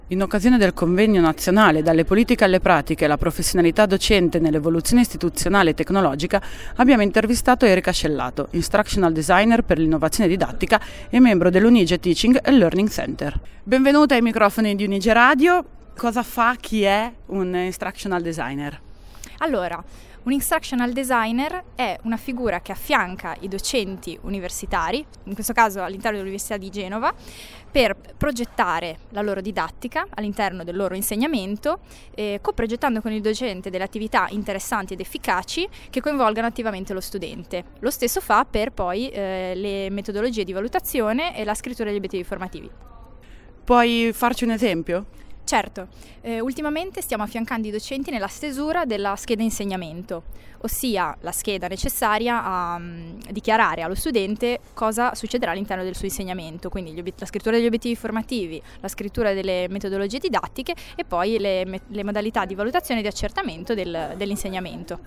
5° Convegno Nazionale sul Faculty Development
Interviste e montaggio